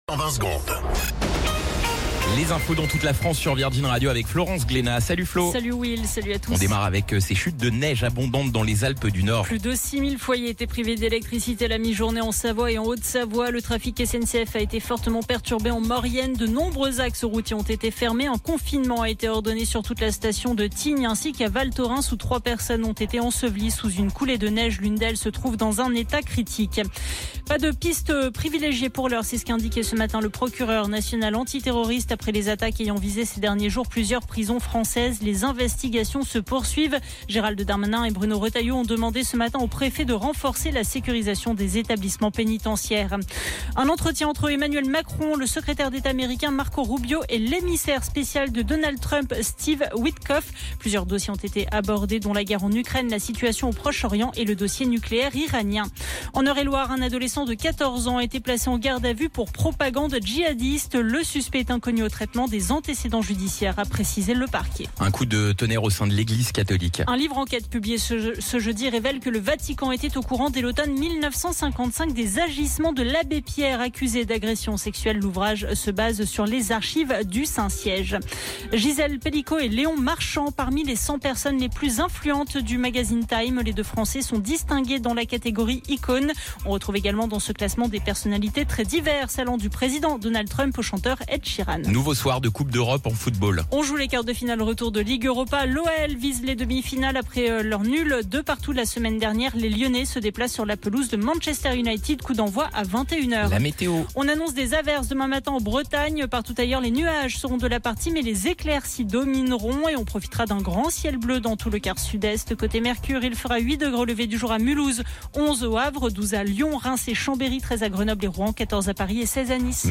Flash Info National 17 Avril 2025 Du 17/04/2025 à 17h10 .